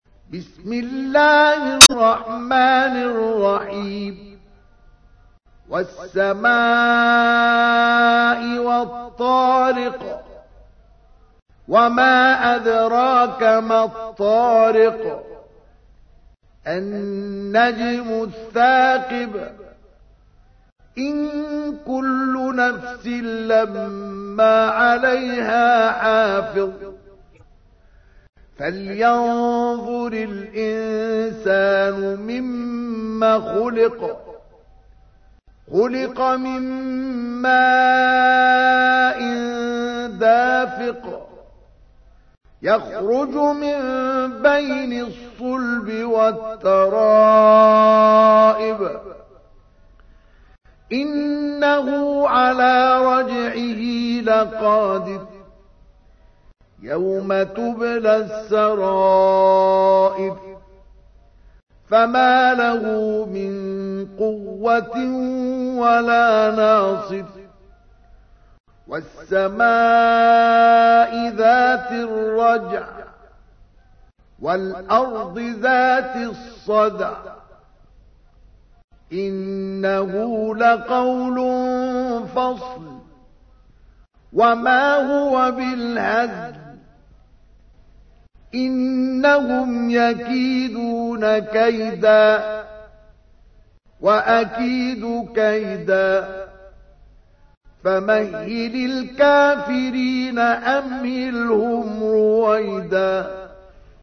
تحميل : 86. سورة الطارق / القارئ مصطفى اسماعيل / القرآن الكريم / موقع يا حسين